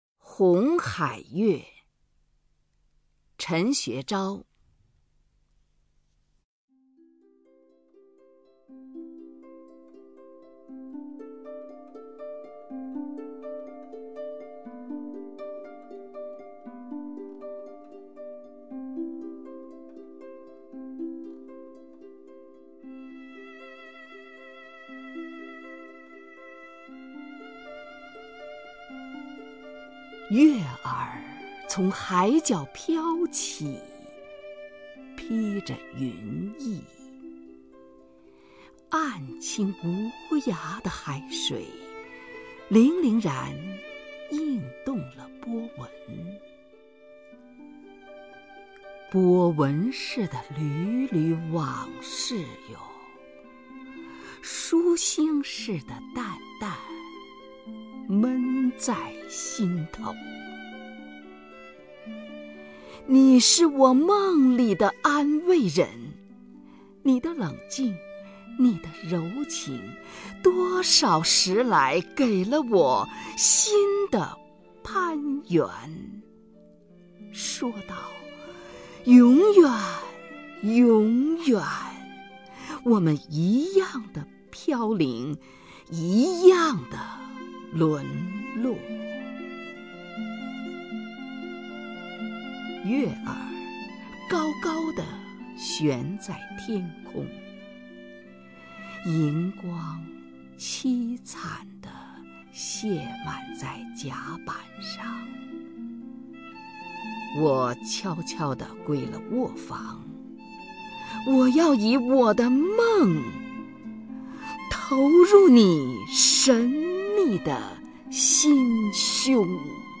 首页 视听 名家朗诵欣赏 虹云
虹云朗诵：《红海月》(陈学昭)